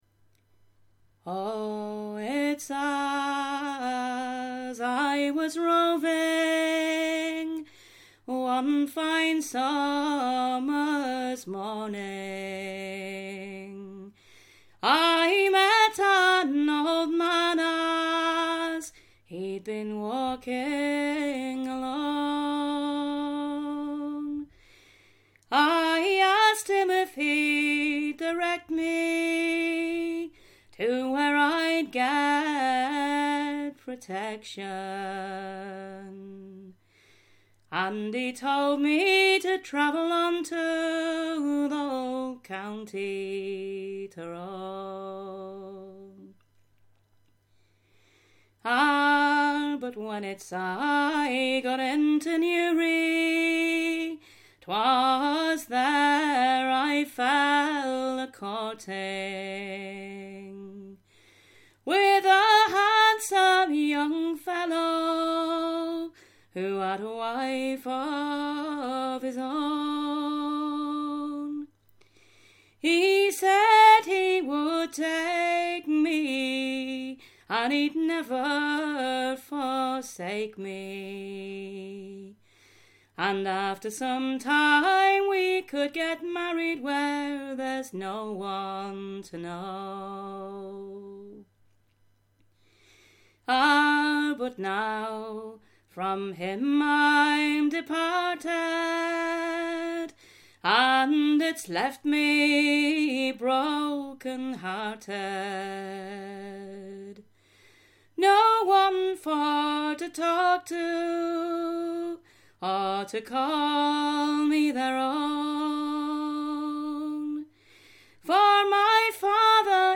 Roving Round the County Tyrone - Amateur